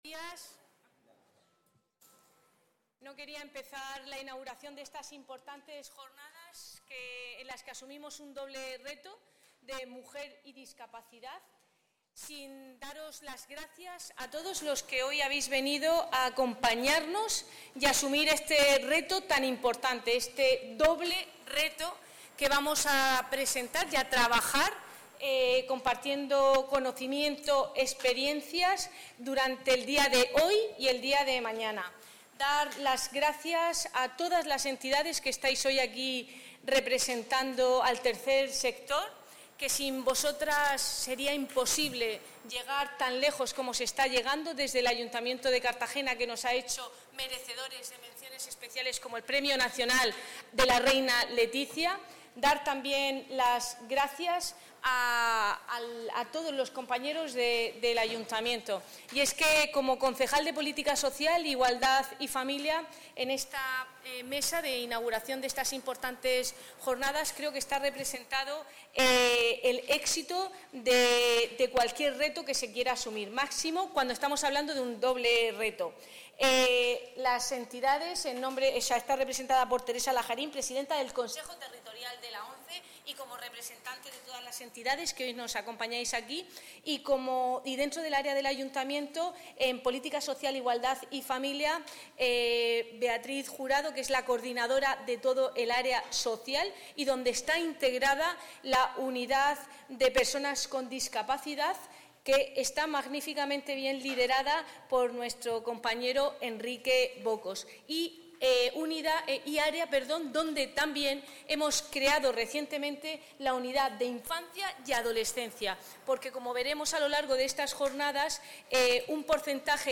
Enlace a Declaraciones de Cristina Mora.
La concejala de Política Social, Cristina Mora, ha asistido este martes a la jornada inaugural.